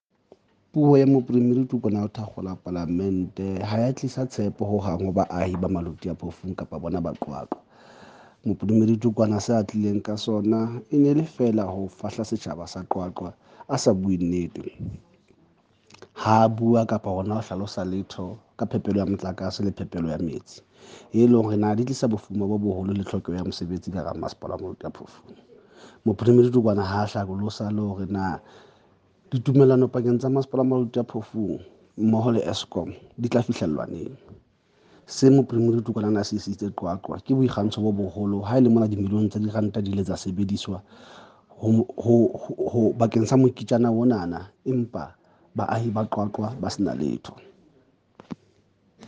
Sesotho soundbite by Moshe Lefuma Cllr